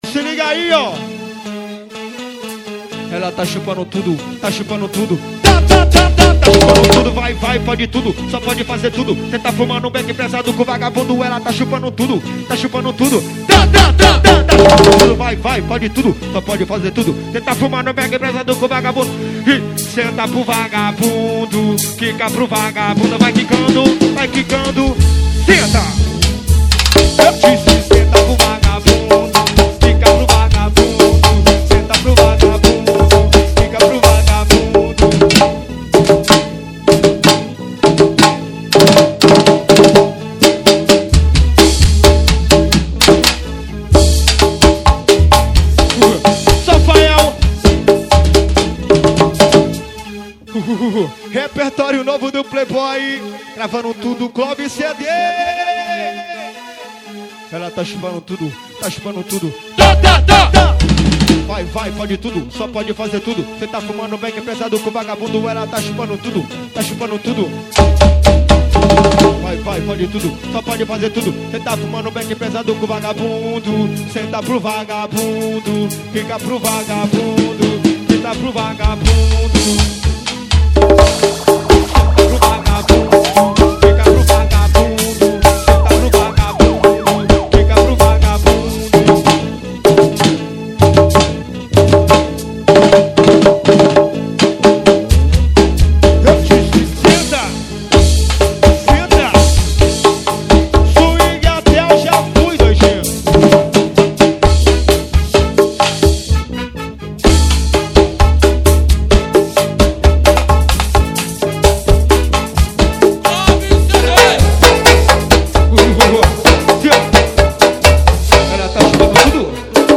2024-12-23 18:31:02 Gênero: MPB Views